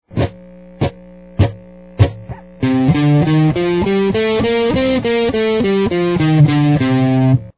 Scales and Modes on the Guitar
C-Dorian.mp3